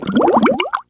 BUBBLES1.mp3